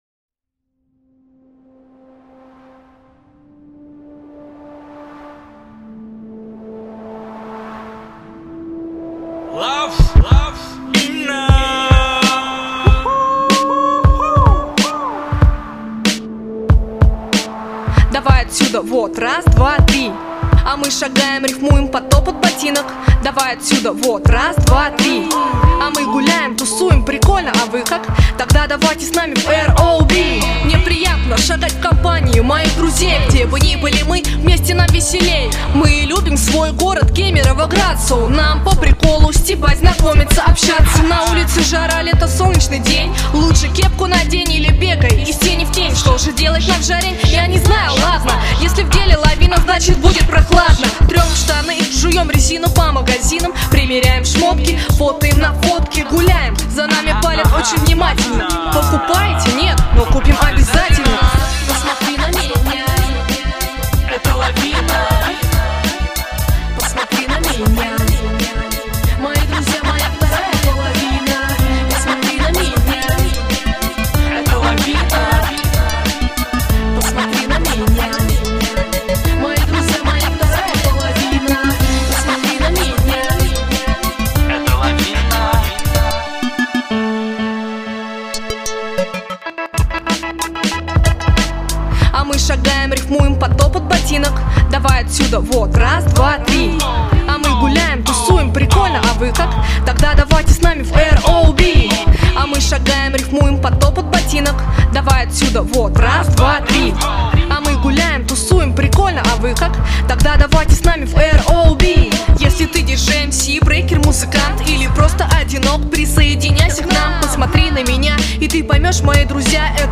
2007 Рэп Комментарии